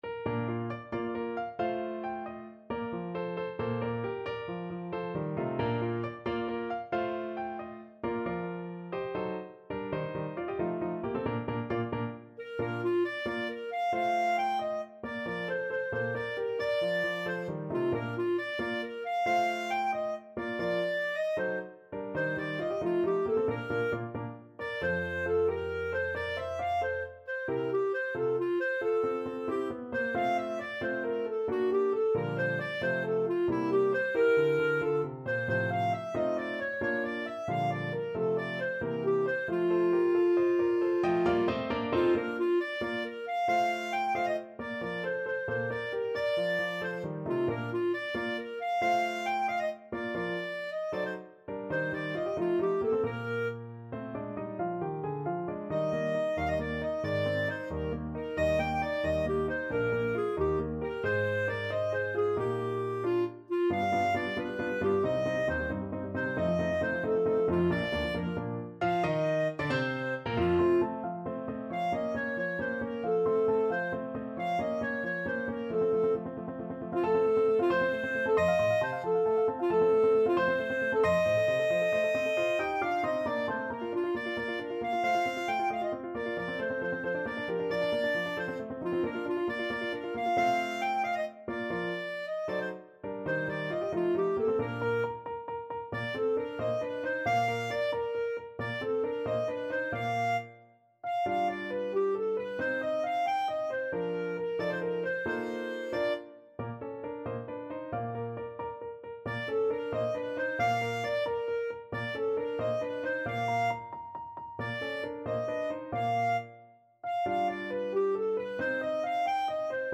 Clarinet
Bb major (Sounding Pitch) C major (Clarinet in Bb) (View more Bb major Music for Clarinet )
. = 90 Allegretto vivace
6/8 (View more 6/8 Music)
Classical (View more Classical Clarinet Music)